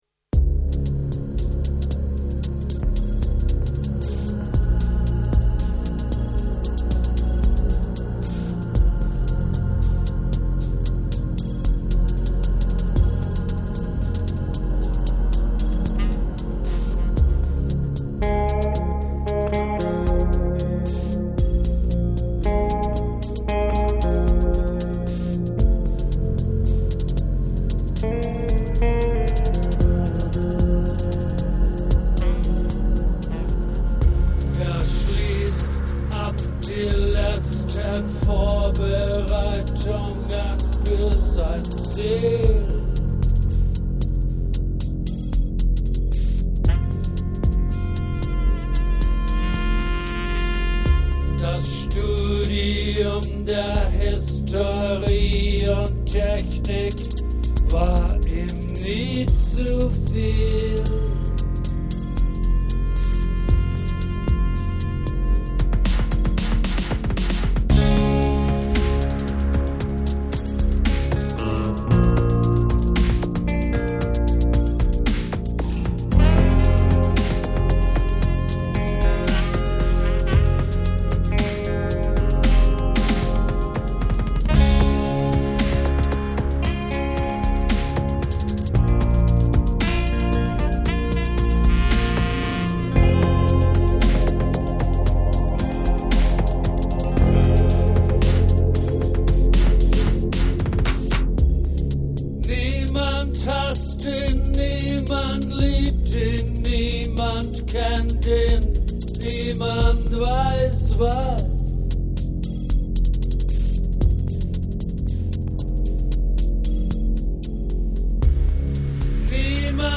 Hier noch ein musikalischer Gruss, ist eine Nummer aus einem Proberaum-Mittschnitt den wir vor 2 Wochen (vor Abbruch unserer Proben gemacht haben).
Sax, Noise
Git, Voc
Bass, Bass-Synth, Drum Programming